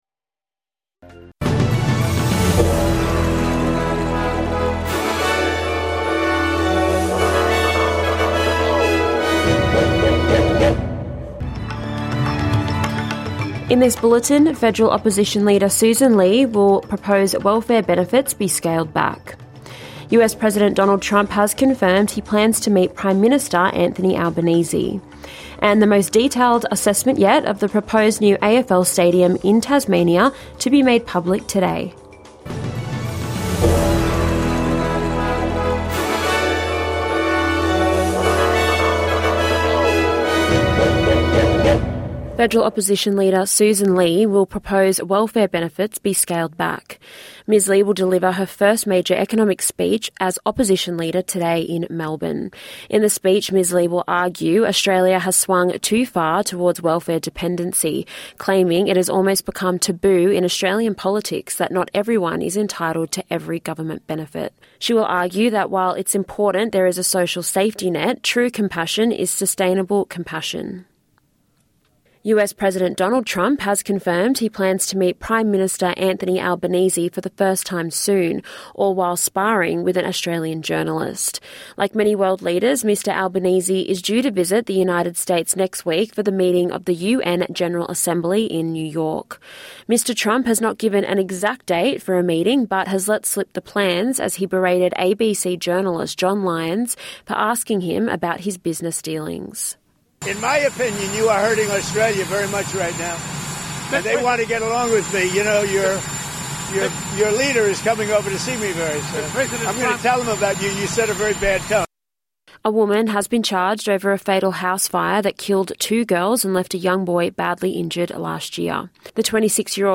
NITV Radio - News 17/9/2025